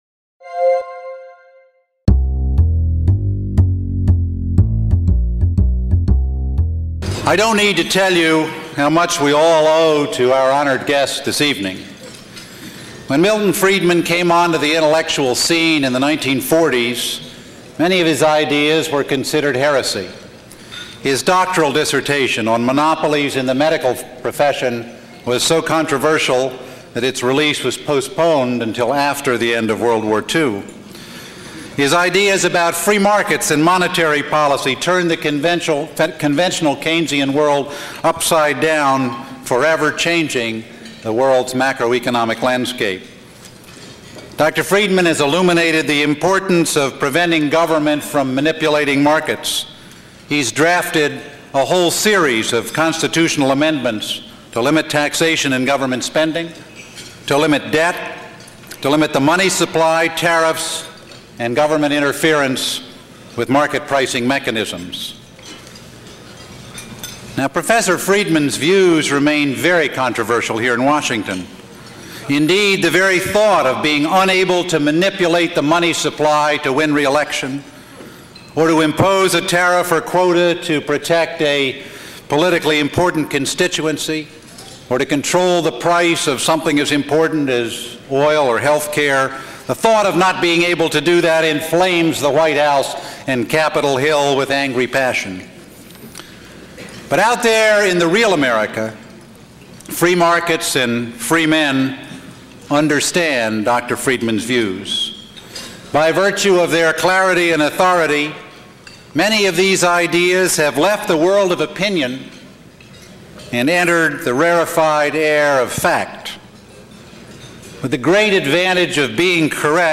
In 1993, at the grand opening of the Cato Institute’s headquarters in Washington, D.C., Dr Milton Friedman gave a talk.
Besides being informative, he is as always delightfully funny and entertaining.